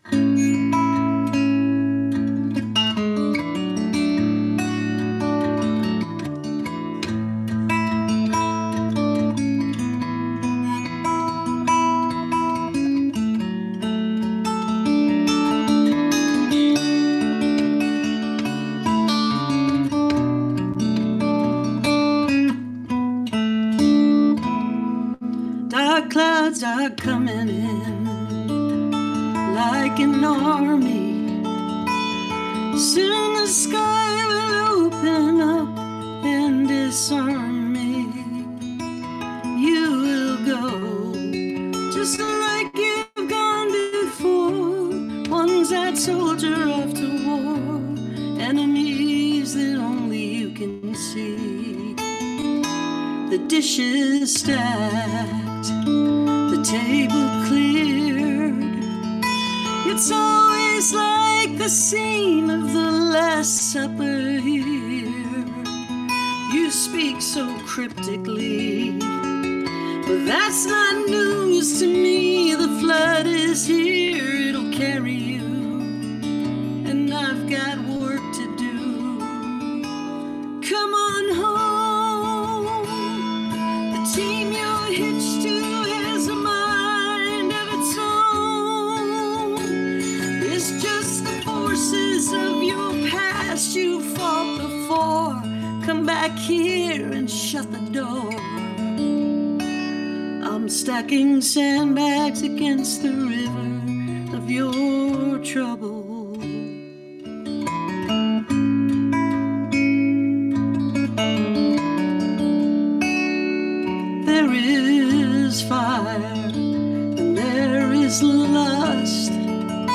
(captured from webcast)